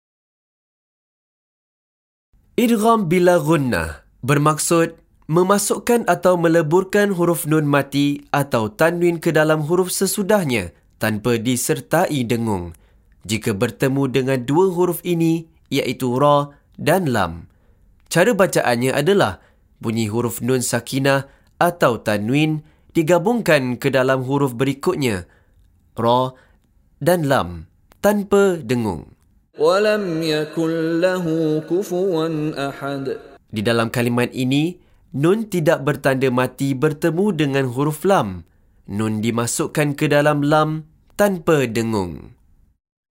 Penerangan Hukum + Contoh Bacaan dari Sheikh Mishary Rashid Al-Afasy
MEMASUKKAN/MENGGABUNGKAN huruf Nun Sakinah/ Tanwin Tidak Selari dengan huruf selepasnya (tanpa dengung)
Bacaan Idgham Bila Ghunnah ialah dengan menggabungkan suara nun ke dalam huruf selepasnya, tanpa didengungkan.